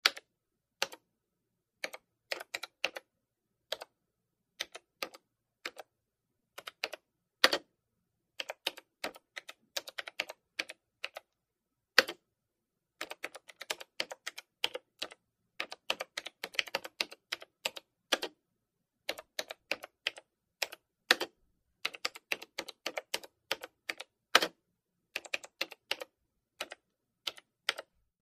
PcKeyboardSlowTaps PE263003
PC Keyboard 3; Desktop Keyboard; Slow / Sporadic Typing, Close Perspective.